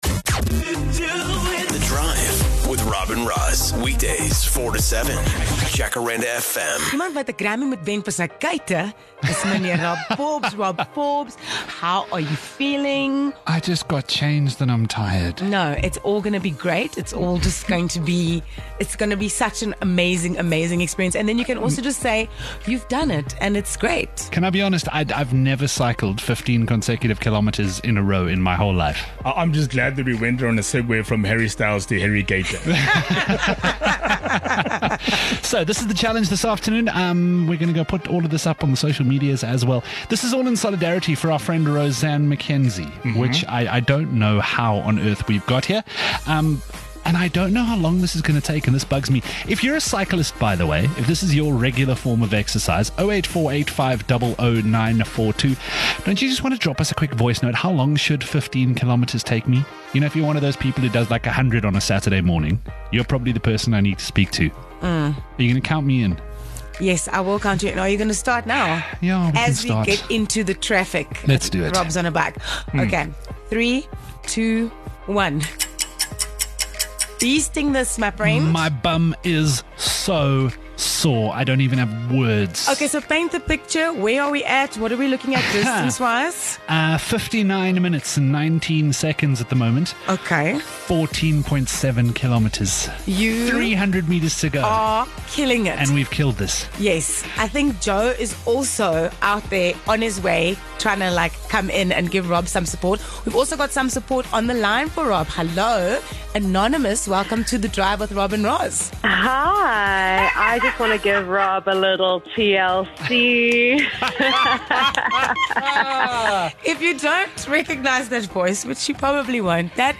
No, they're just exercising during the show!
Plot twist - they're doing it while on air!